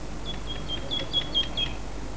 Here are a few samples of birds songs I recorded in the hotel's gardens.